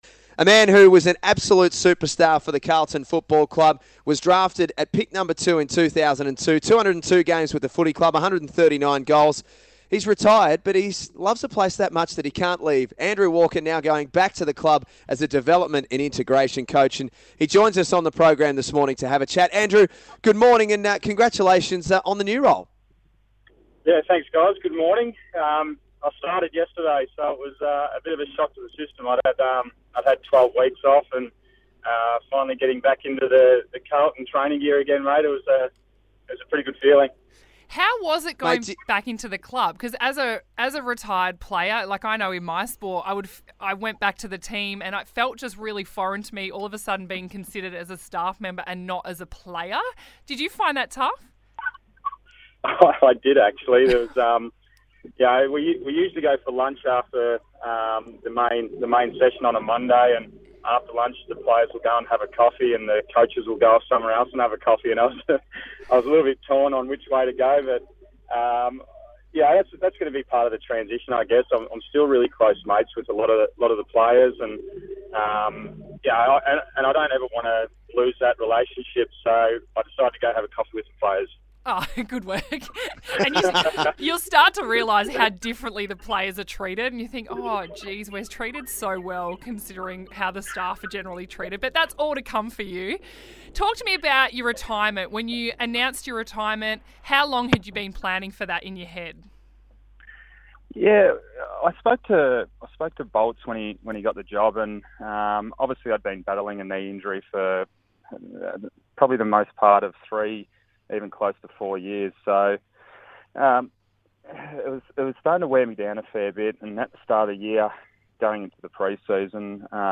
He's back! The recently retired Blue chats to The Morning Rush about his new role at the Club as a development and integration coach, as the 2017 pre-season gets underway.